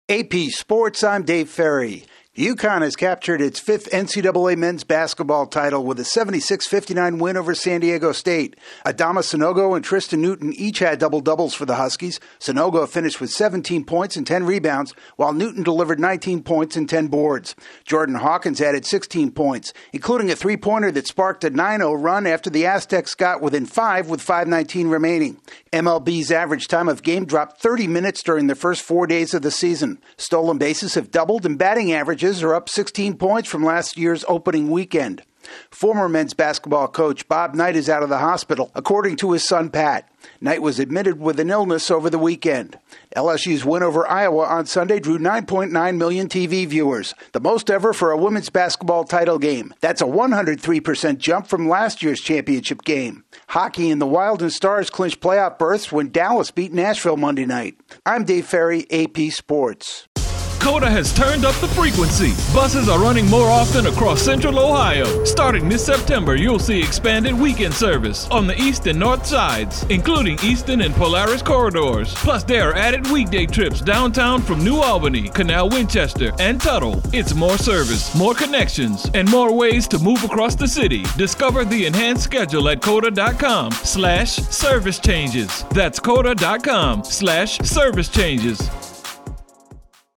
Connecticut completes a March Madness title run, MLB showing speed, Legendary hoops coach feeling better, big ratings for women’s hoops and the Stars and Wild reach the postseason. AP correspondent